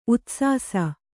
♪ utsāsa